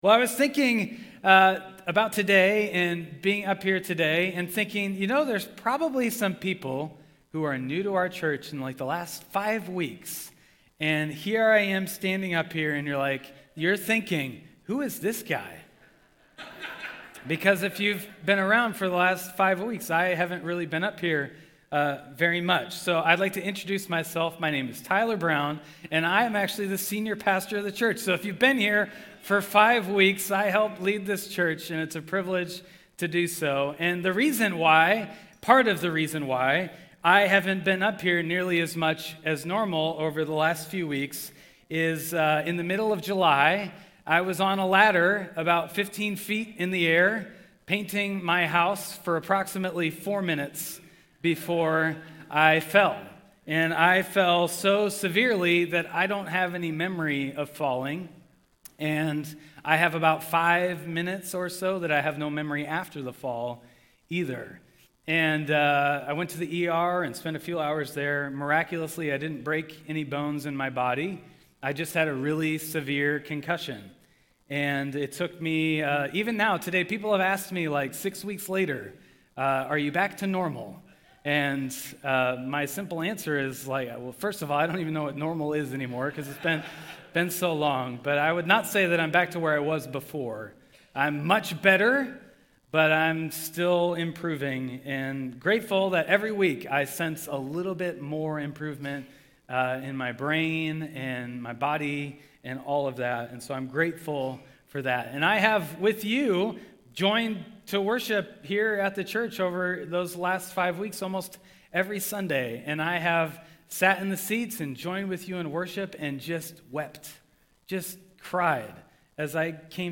Matthew 6:1-6 (2025 Stand Alone Sermon)